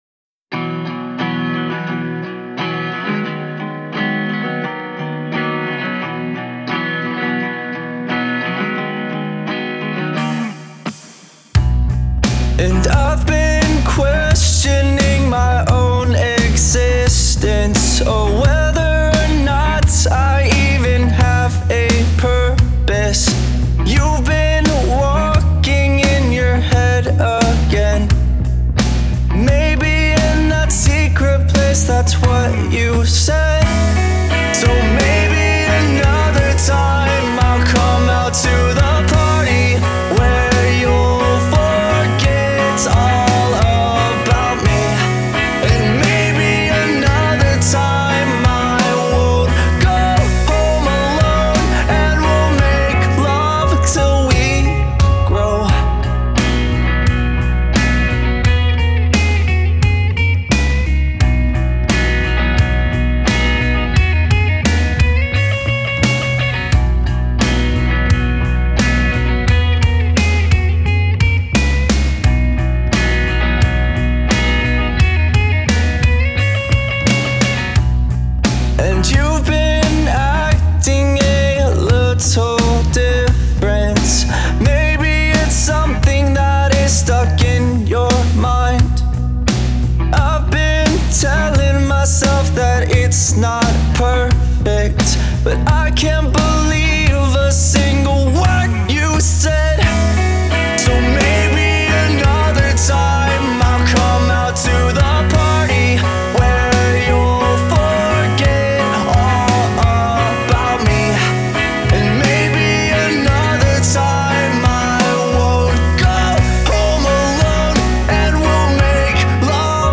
alternative pop rock song